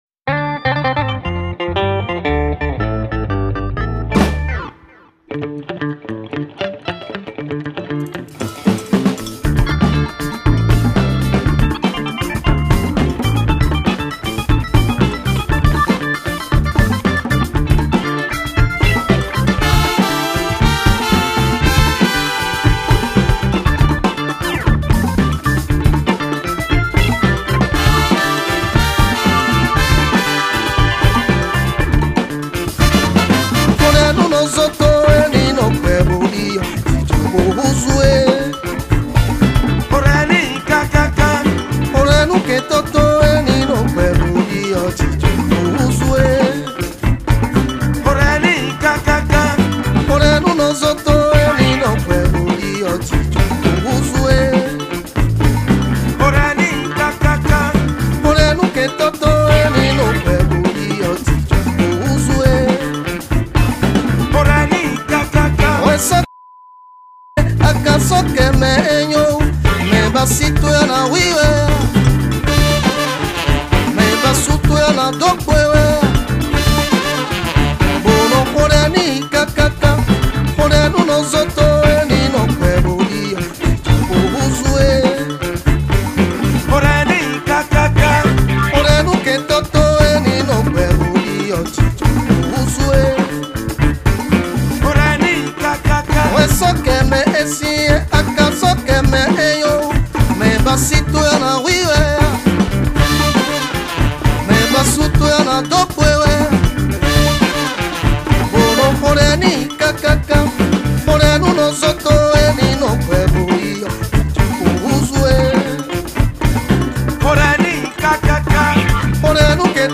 Music Review